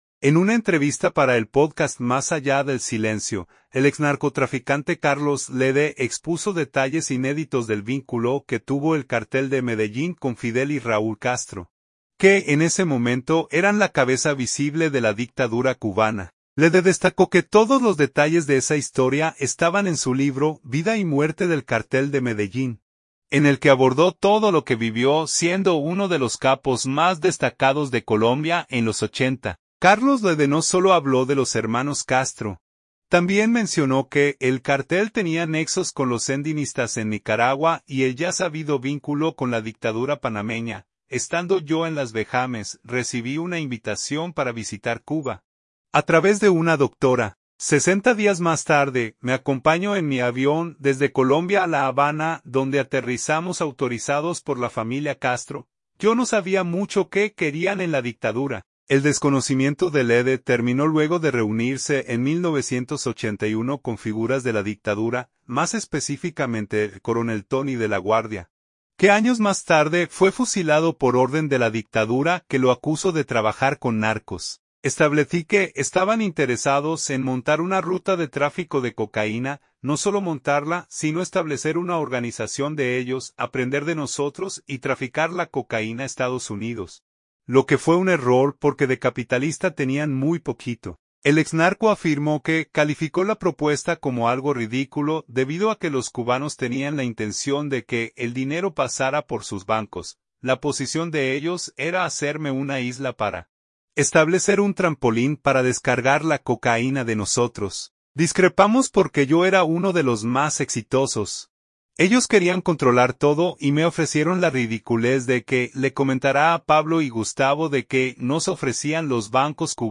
En una entrevista para el pódcast Más allá del silencio, el exnarcotraficante Carlos Lehder expuso detalles inéditos del vínculo que tuvo el cartel de Medellín con Fidel y Raúl Castro, que en ese momento eran la cabeza visible de la dictadura cubana.